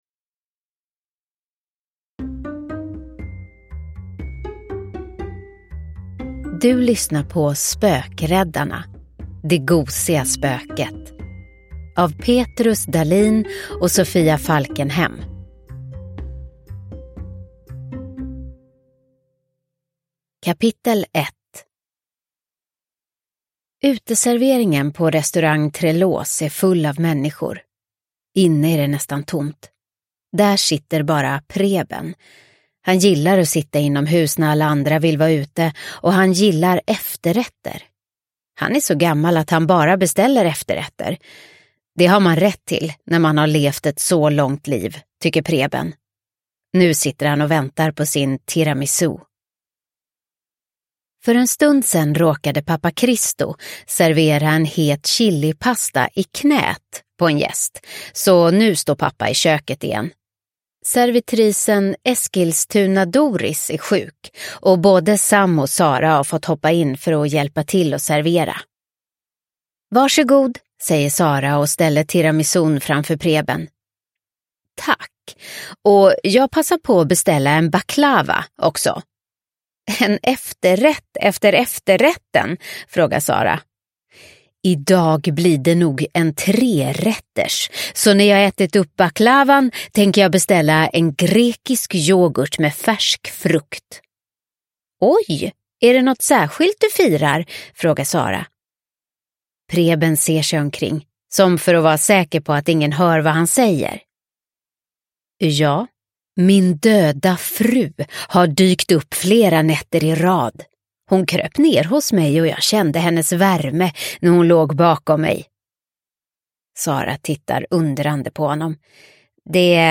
Det gosiga spöket – Ljudbok – Laddas ner